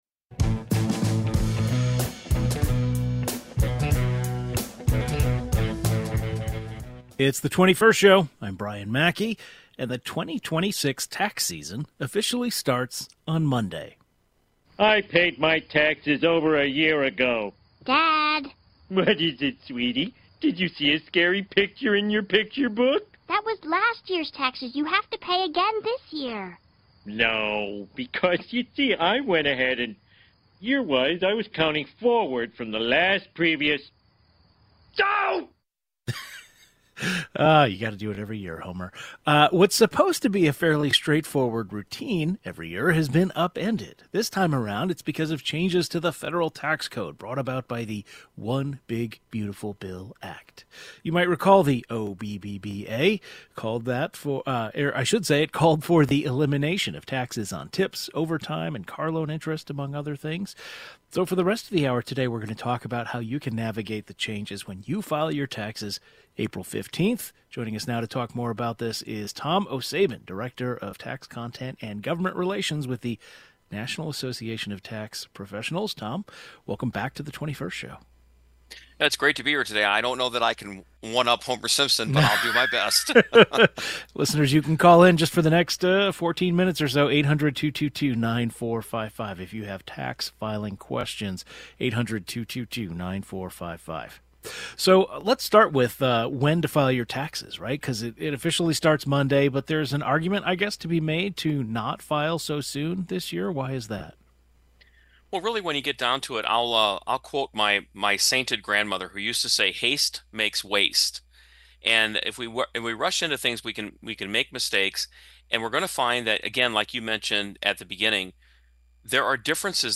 The 21st Show is Illinois' statewide weekday public radio talk show, connecting Illinois and bringing you the news, culture, and stories that matter to the 21st state.
Normally this would be a pretty simple, mundane conversation, but filing looks quite a bit different this year, because of new tax policy created by the One Big Beautiful Bill Act. A tax professional discusses how to navigate all the changes.